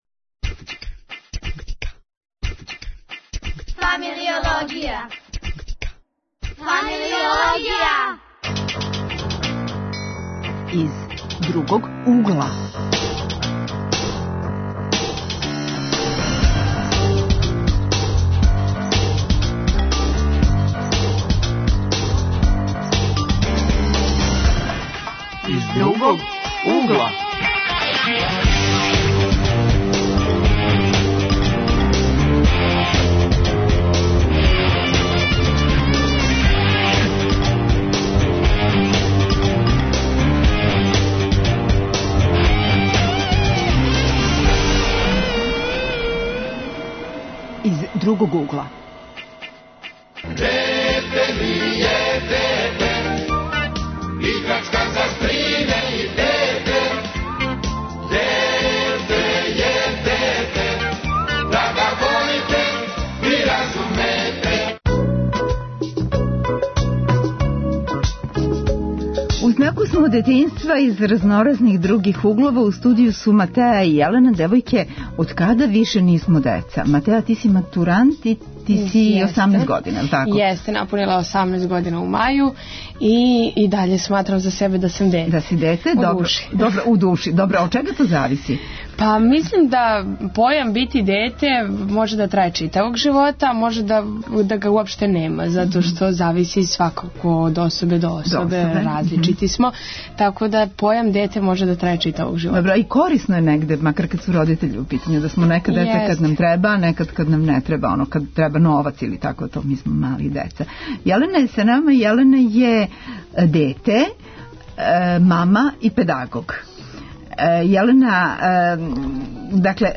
Тема - детињство из разноразних 'других углова', гости - средњошколци, студенти, родитељи...